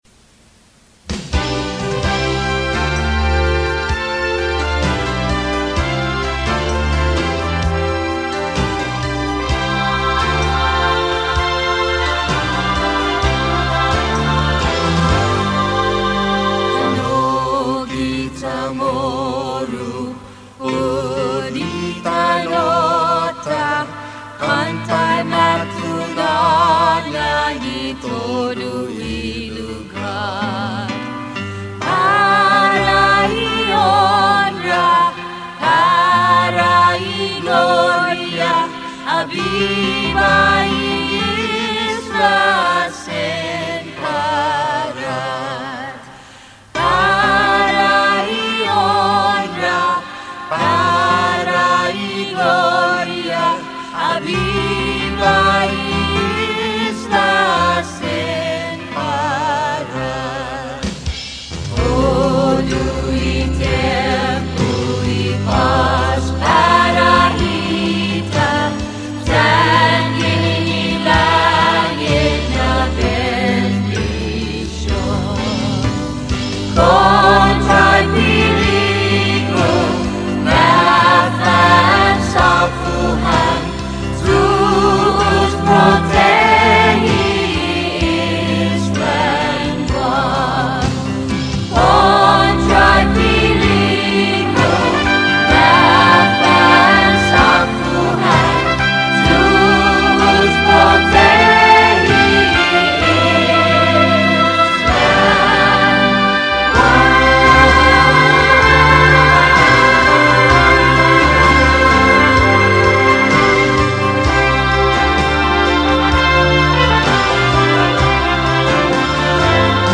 Sung by: